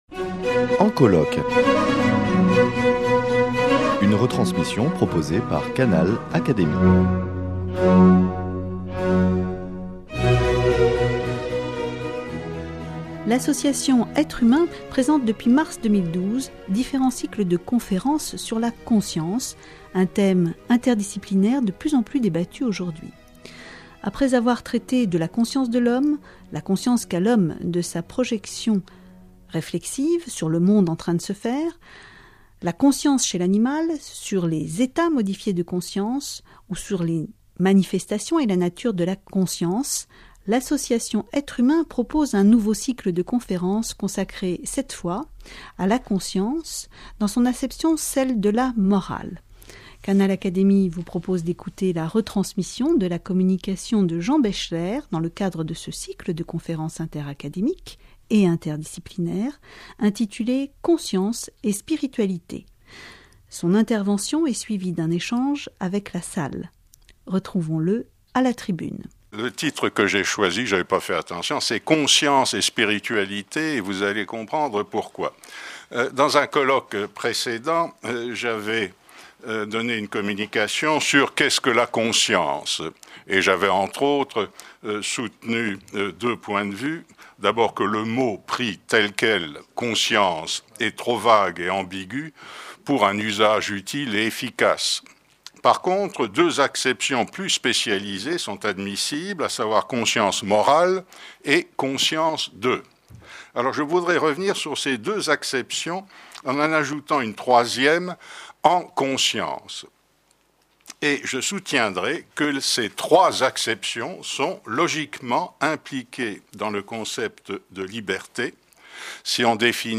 Le sociologue, membre de l’Académie des sciences morales et politiques, Jean Baechler était invité par l’association "Être humain" dans le cadre d’un cycle de conférences consacré à la conscience morale. Il présente sa conception de la conscience terme trop ambigu et trop vague pour un usage efficace à ses yeux.
Qu’impliquent la liberté, la conscience morale et la recherche de l’absolu ? Écoutez ses réponses et l’échange qui a suivi son intervention.